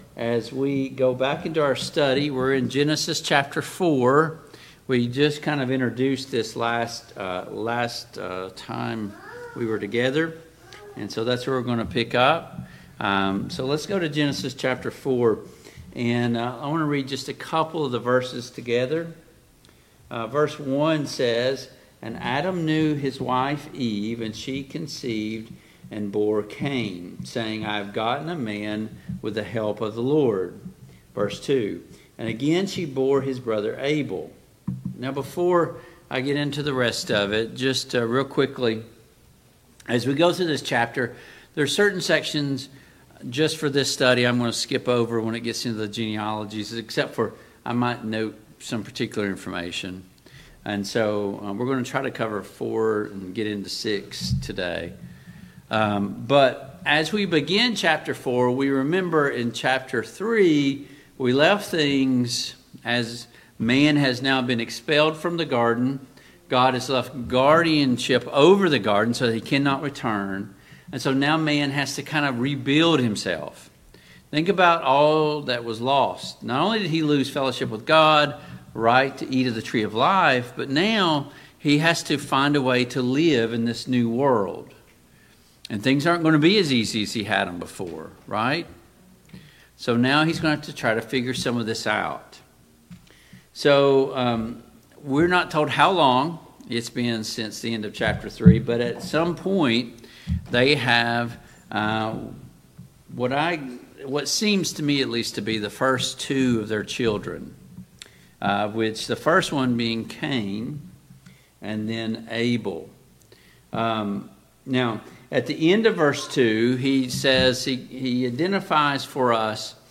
Genesis 6 Service Type: Family Bible Hour Topics: Cain and Abel , Violence « Beware of the Lies of Satan 16.